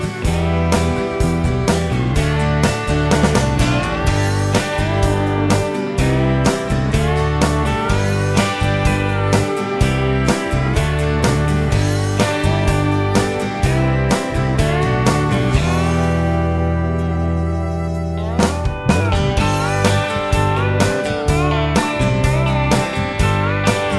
no Backing Vocals Country (Male) 4:18 Buy £1.50